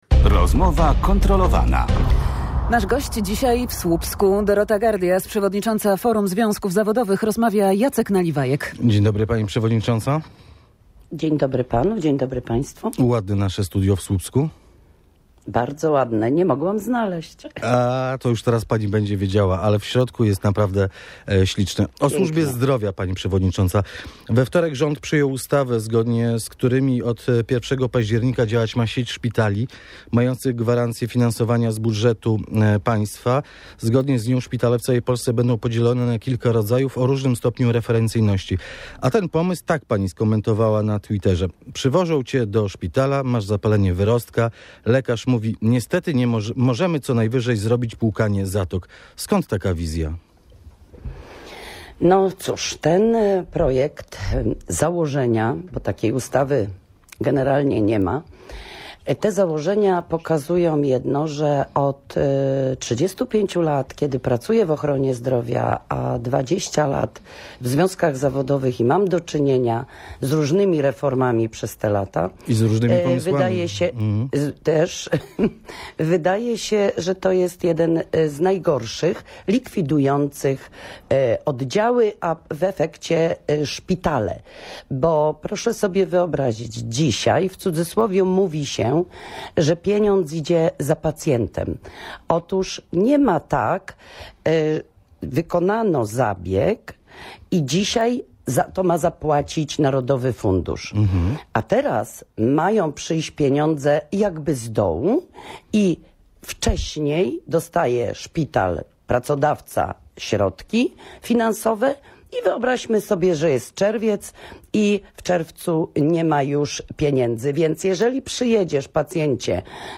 Sprawę w Rozmowie Kontrolowanej komentowała Dorota Gardias, przewodnicząca Forum Związków Zawodowych.